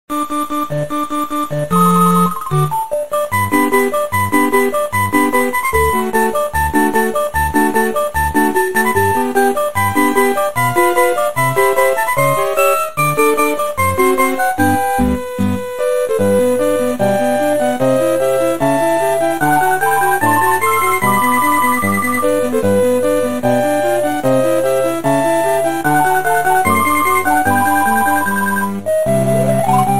The title screen music